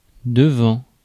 Ääntäminen
US : IPA : [ˌaʊt.ˈsaɪd]